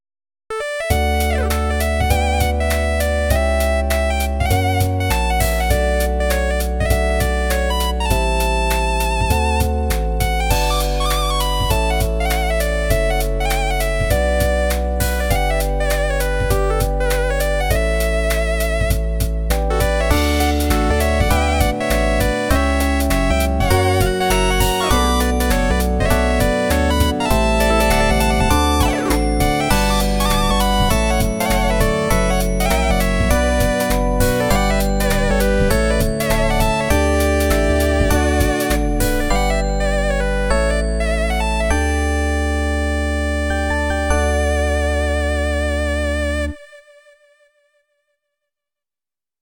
デモソング
CureSynth™mini用のデモソングです。